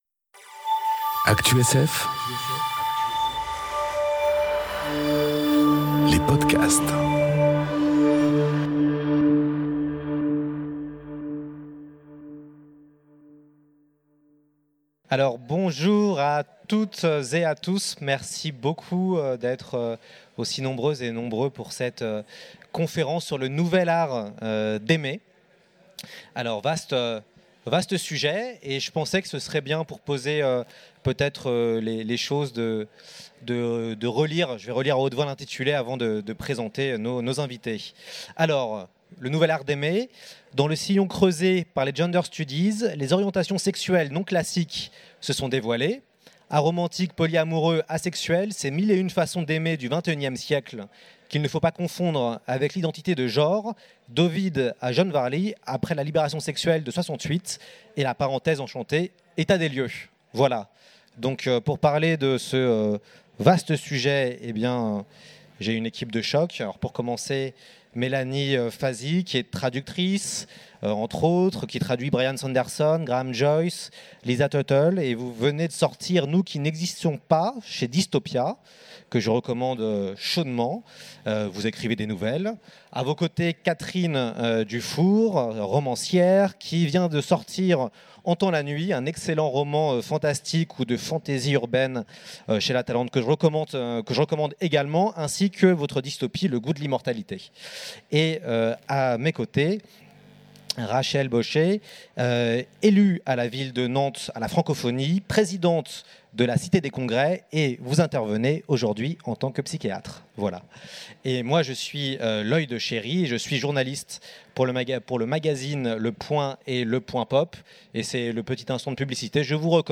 Conférence Le nouvel art d’aimer enregistrée aux Utopiales 2018